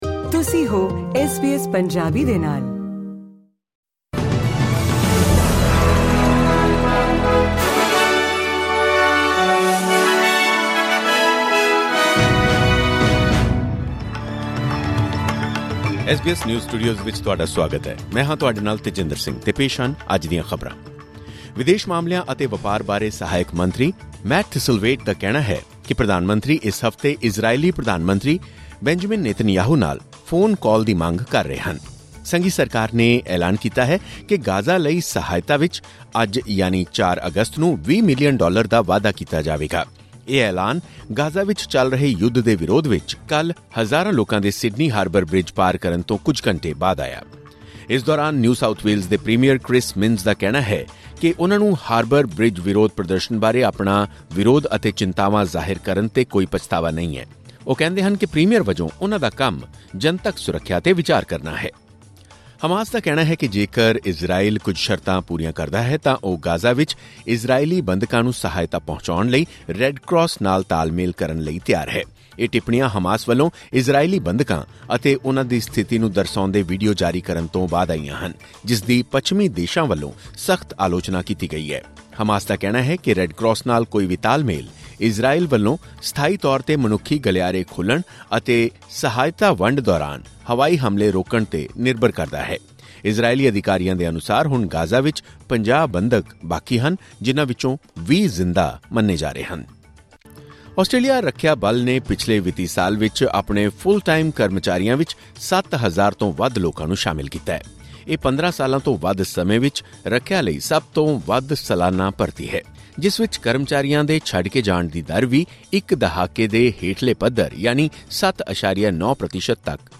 ਖਬਰਨਾਮਾ: ਲਗਭਗ ਚਾਰ ਸਾਲਾਂ ਵਿੱਚ ਪਹਿਲੀ ਵਾਰ ਵਧਿਆ ਆਸਟ੍ਰੇਲੀਆਈ ਰੱਖਿਆ ਬਲ ਦਾ ਆਕਾਰ